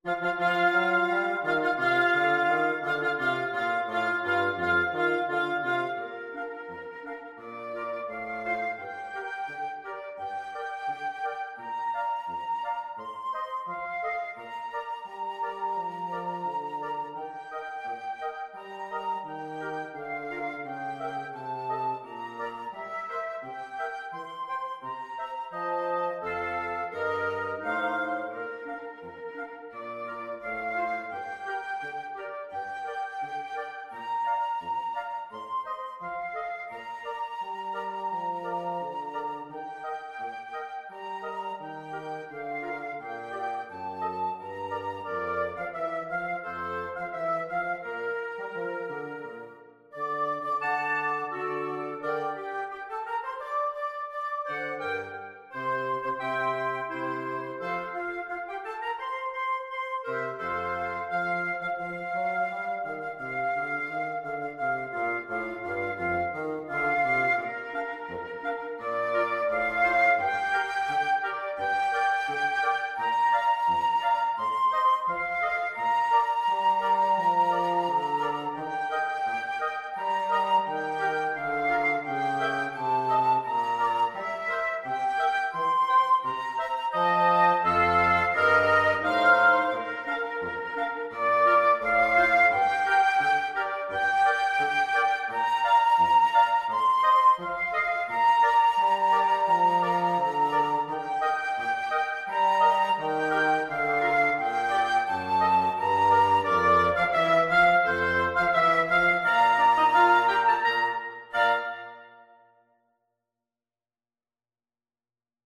FluteOboeClarinetBassoon
2/2 (View more 2/2 Music)
March Tempo - Moderato = c.86